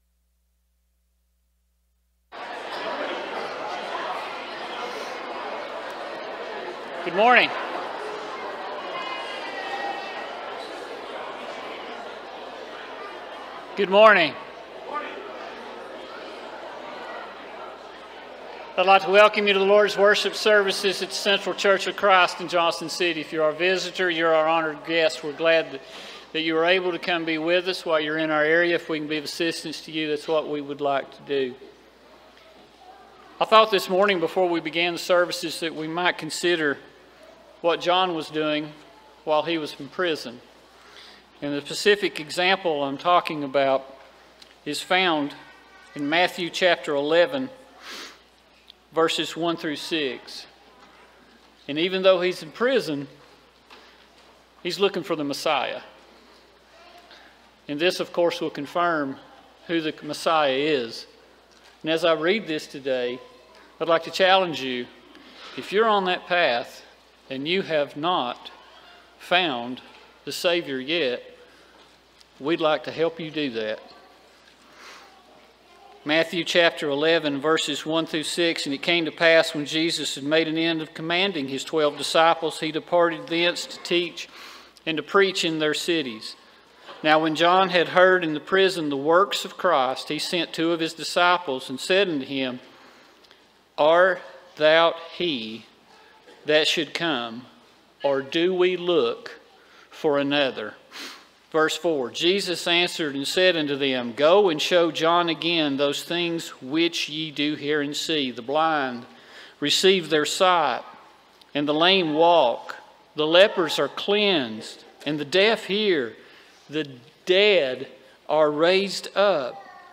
Matthew 11:28, English Standard Version Series: Sunday AM Service